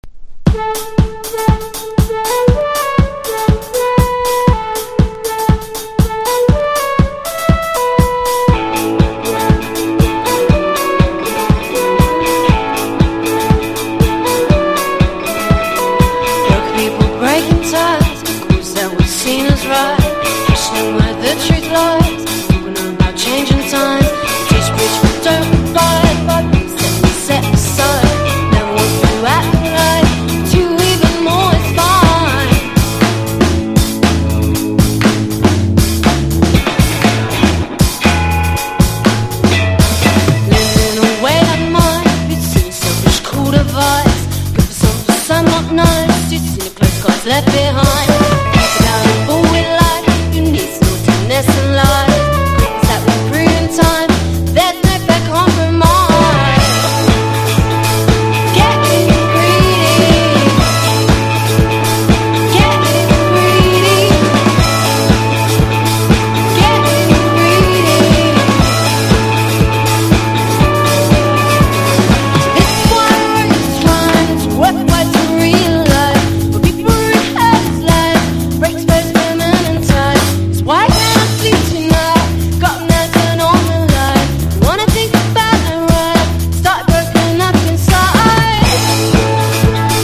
# NEO ACOUSTIC / GUITAR POP
インディ感漂うゆるゆるダンサブル・ポップ・チューン！！